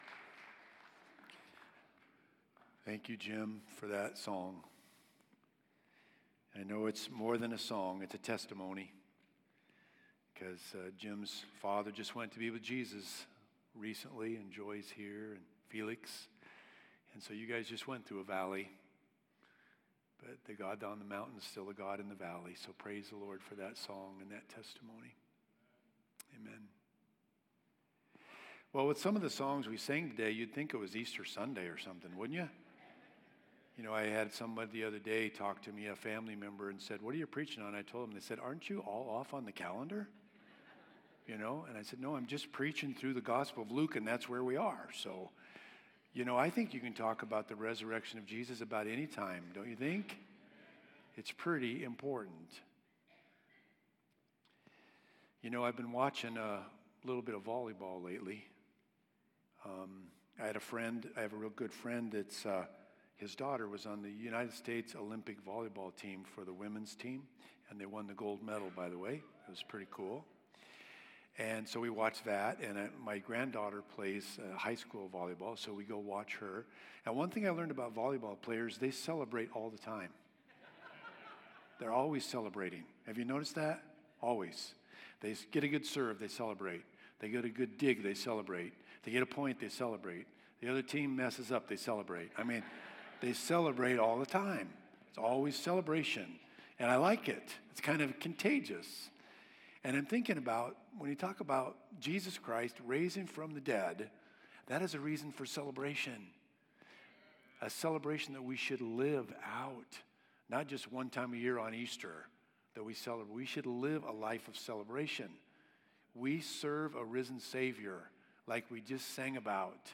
8.29.21-Sermon.mp3